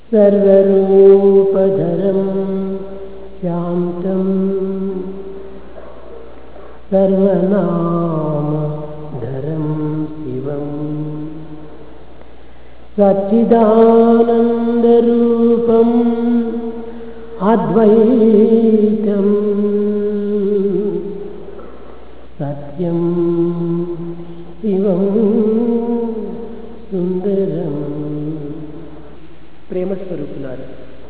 Bhagavan Sri Sathya Sai Baba gave a series of regular discourses every evening in the Sai Kulwant Hall during June - July, 1996.